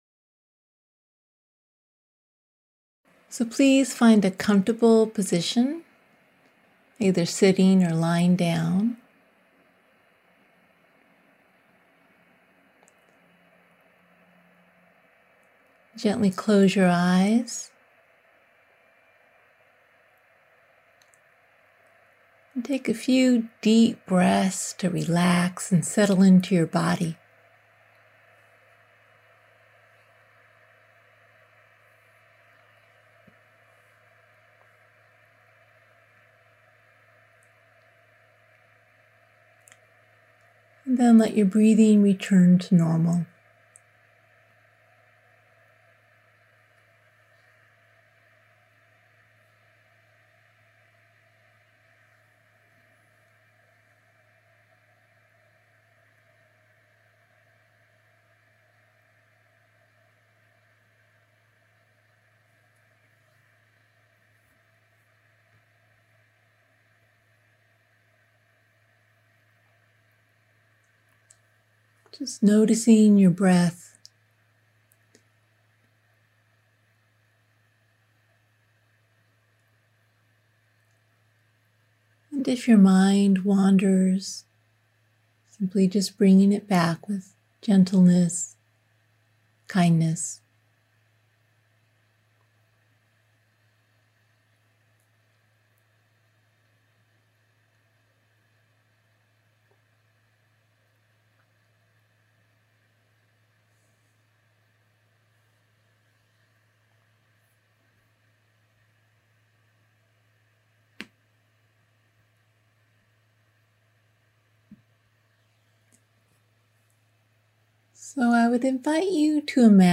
This guided visualization helps you meet an inner fiercely compassionate friend who can help you find the courage needed to take action.